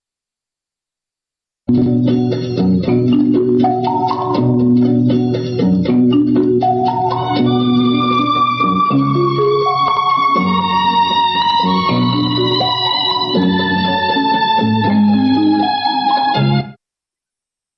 Есть махонький отрывок оркестровки 60-х годов. Может, кто подскажет, кто это ?